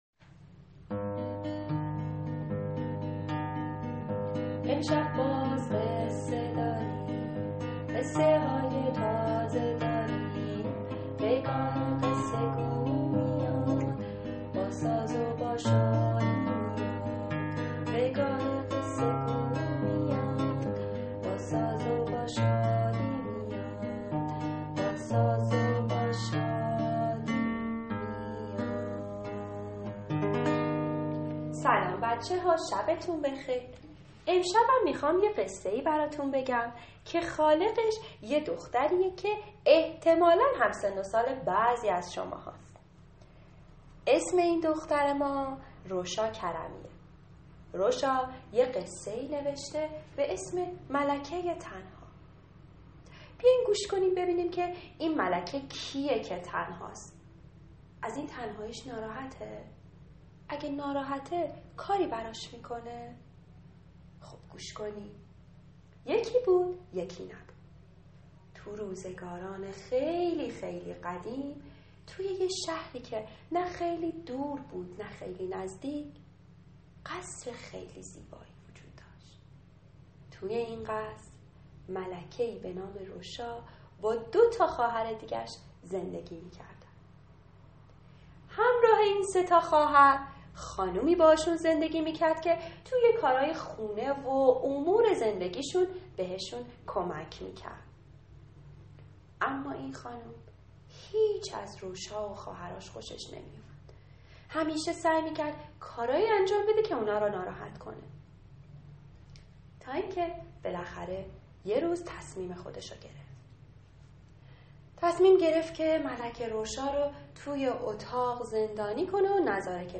قصه صوتی کودکان دیدگاه شما 515 بازدید